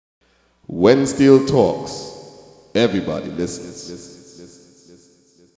In fact, without our permission, in this recent 2014 panorama, WST’s voice tag (
steeltalksmale.mp3